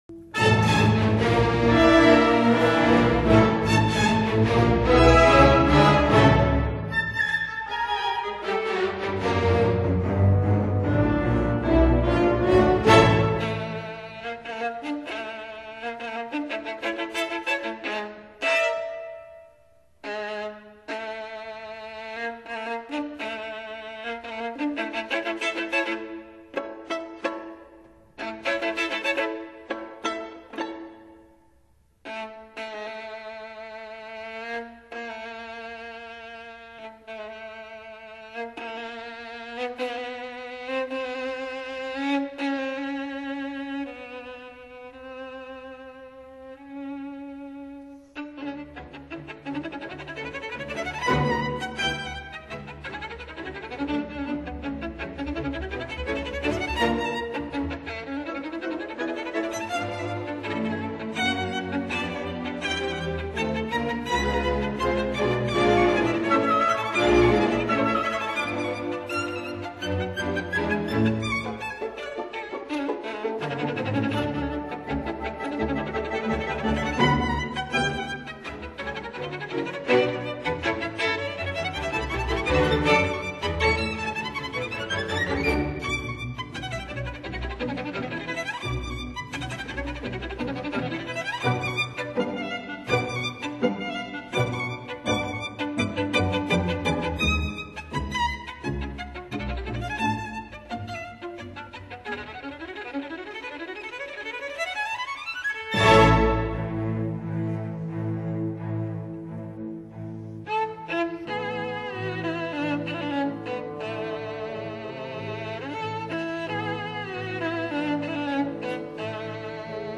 violin
Recorded April 1967, London